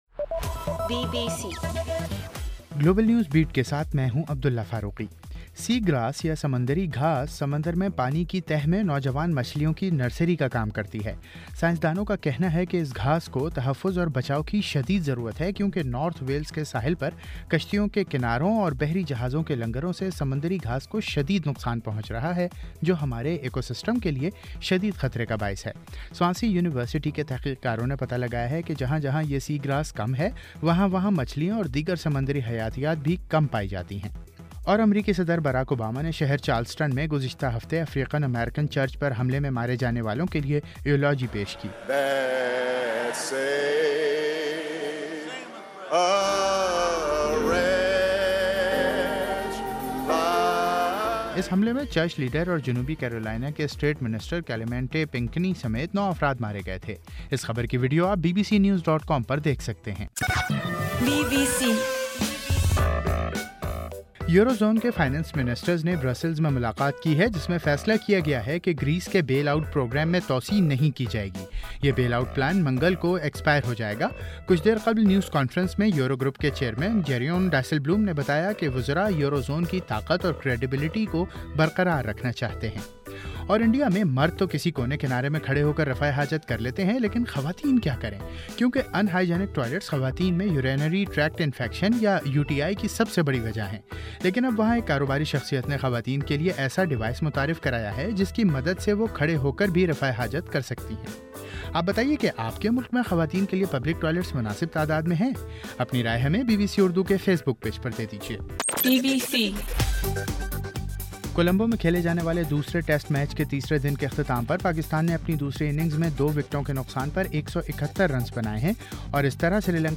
بُلیٹن